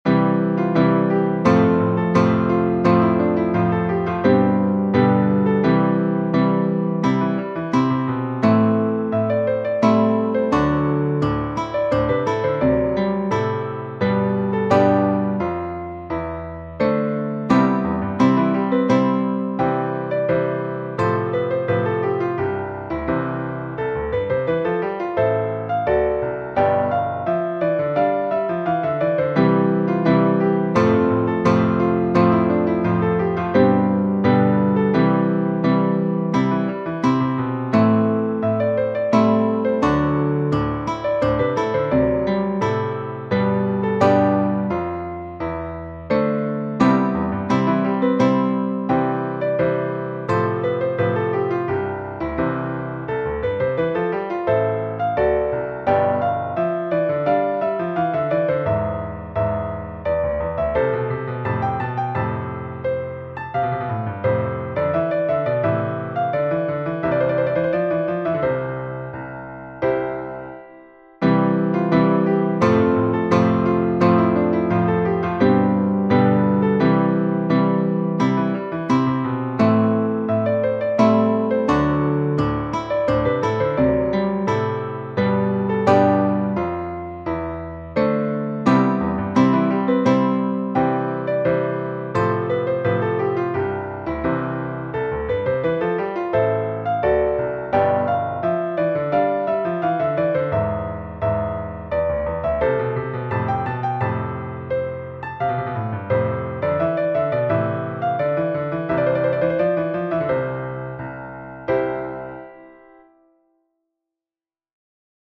Ensayo creado utilizando una aplicación para componer música MIDI llamada Musecore
Una pieza muy sencilla arreglada para piano.